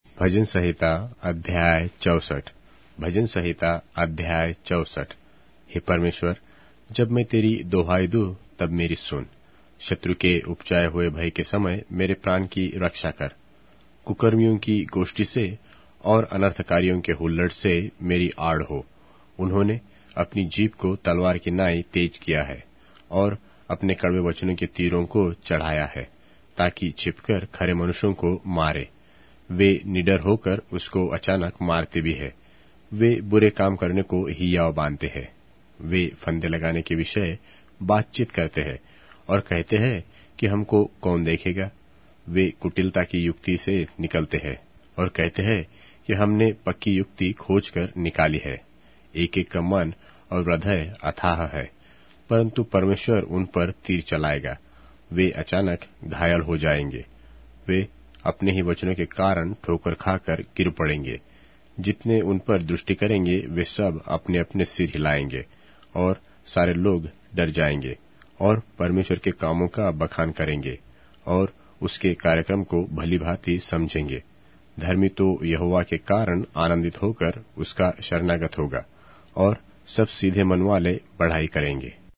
Hindi Audio Bible - Psalms 80 in Ervml bible version